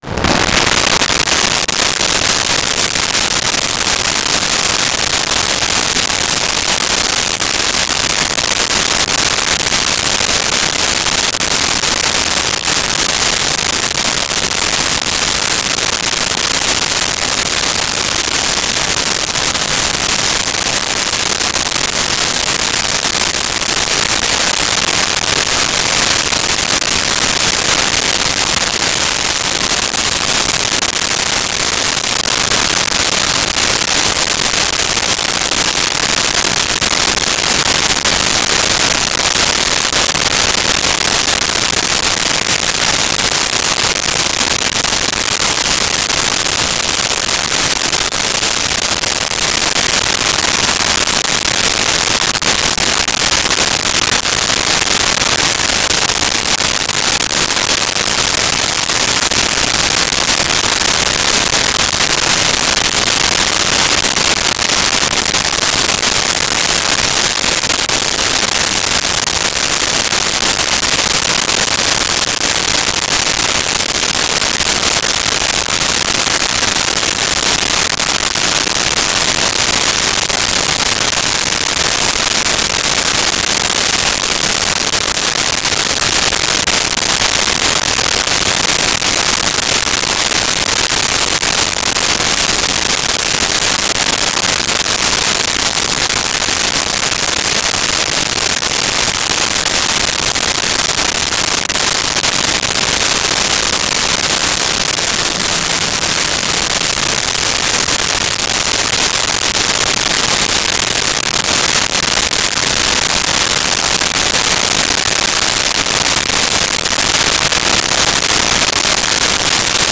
City Traffic Close
City Traffic Close.wav